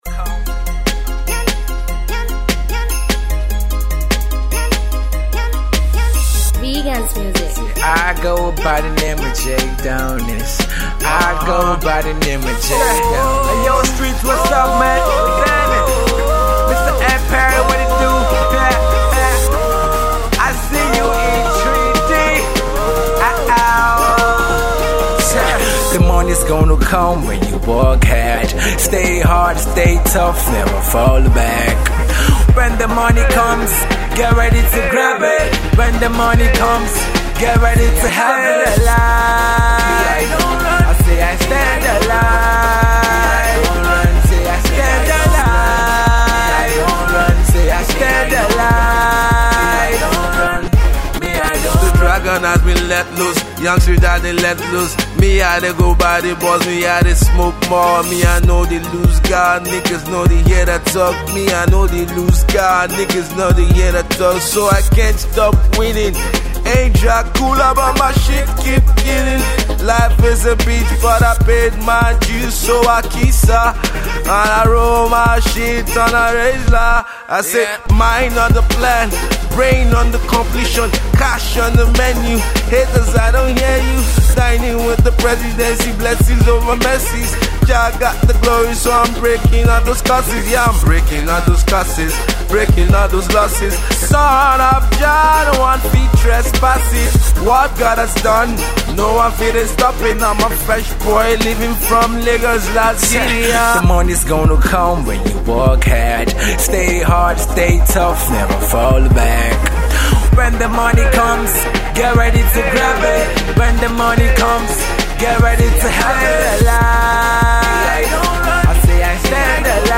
Upcoming Rapper